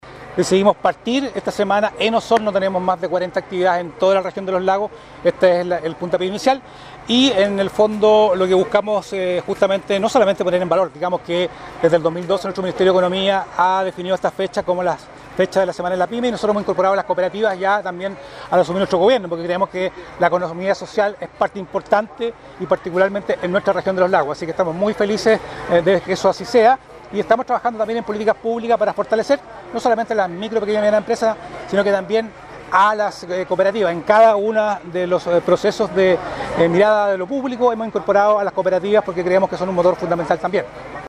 Así lo declaró el Seremi de Economía, Fomento y Turismo, Luis Cardenas Mayorga